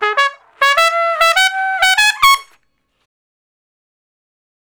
087 Trump Straight (Db) 08.wav